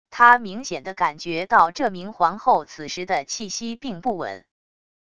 他明显的感觉到这明皇后此时的气息并不稳wav音频生成系统WAV Audio Player